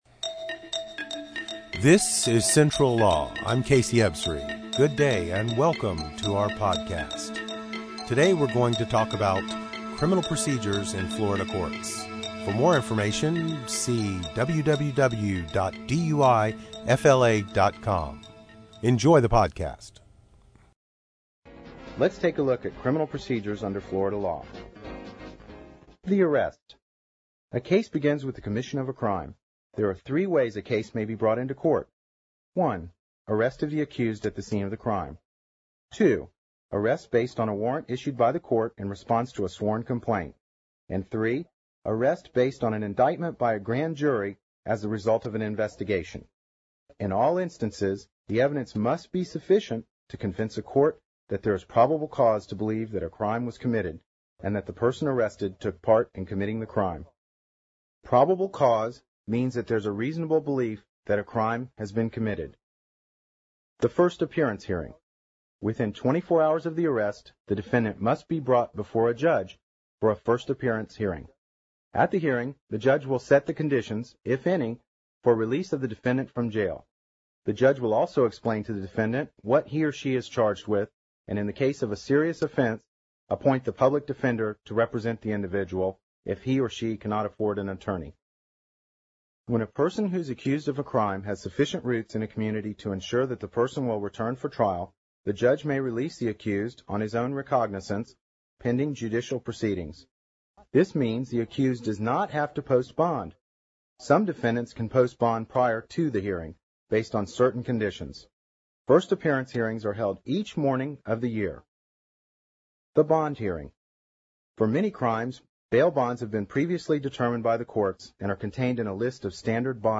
DUI Progression Described By a Board Certified Lawyer Go Over Each Step of a DUI in Tampa Courts
Florida Criminal Procedure described by an expert.
Listen to Expert DUI Attorney Describe the Florida Criminal Procedure